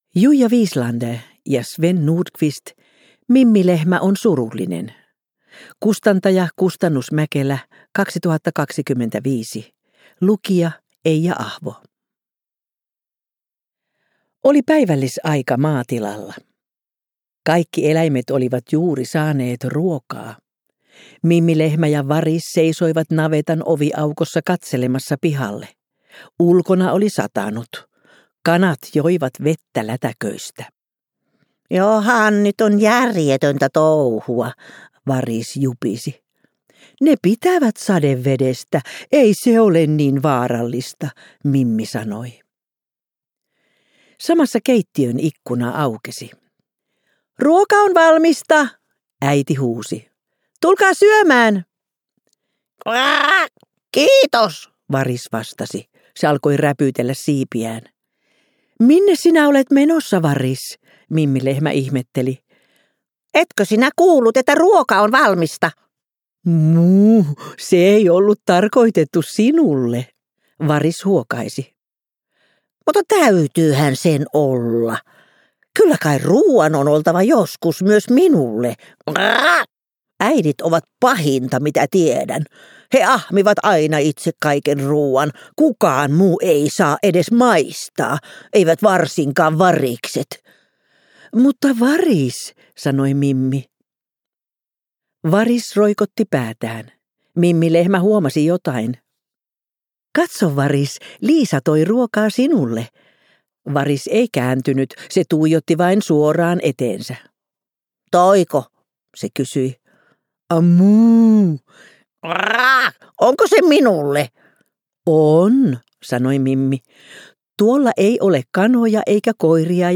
Kuunneltavissa myös äänikirjana useissa eri äänikirjapalveluissa